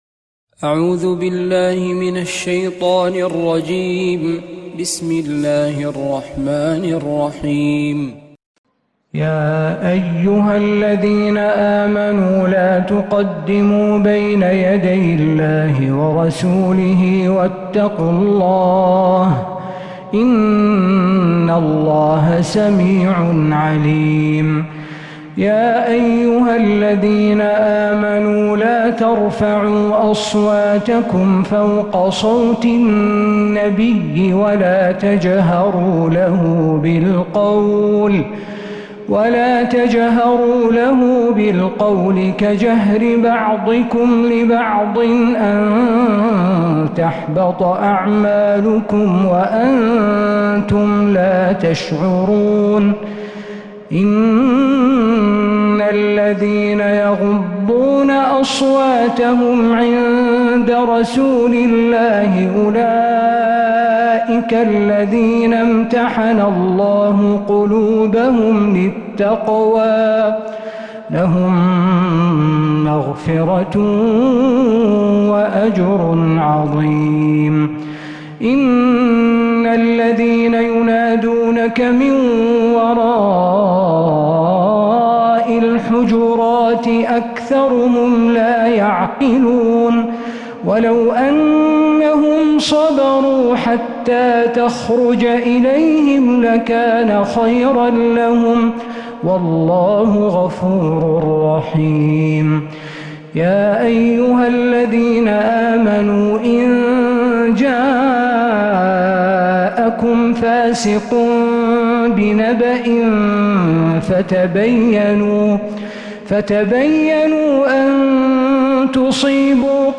من الحرم النبوي 🕌